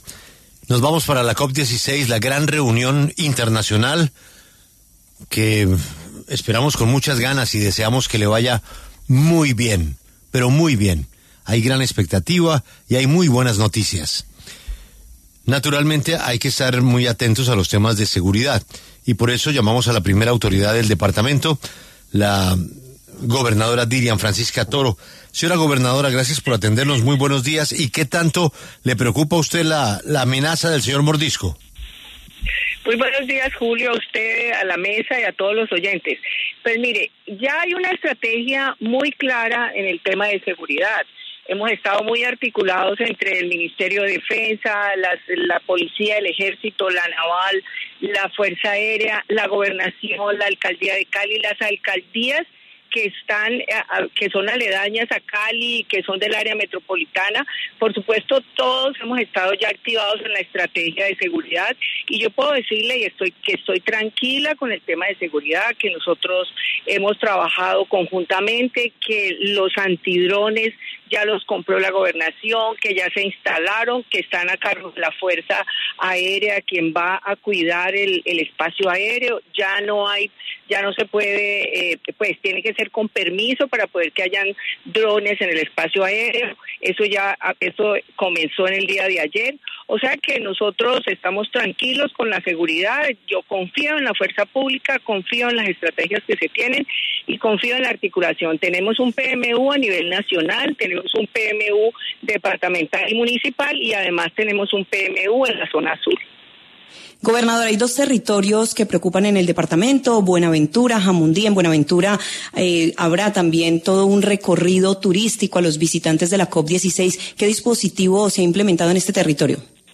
Dilian Francisca Toro, gobernadora del Valle, pasó por los micrófonos de La W para hablar sobre las estrategias de seguridad que se están llevando a cabo en la región.